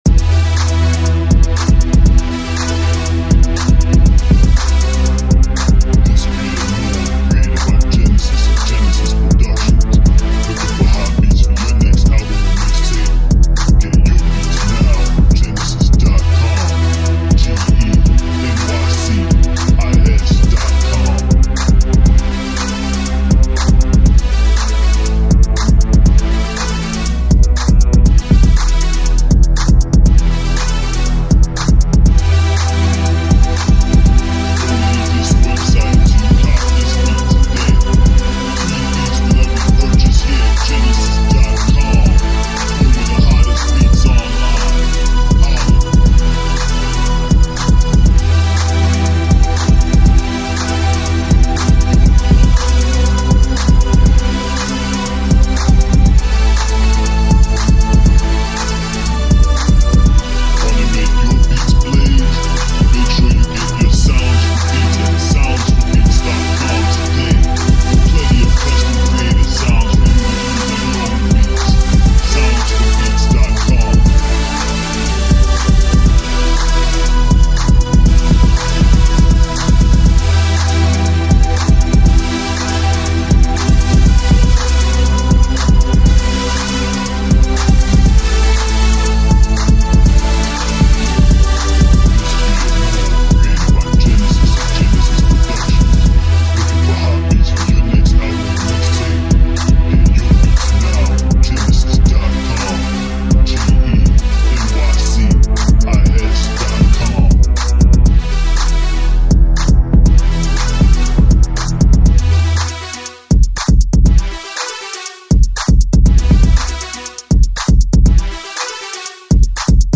Seductive Club Instrumental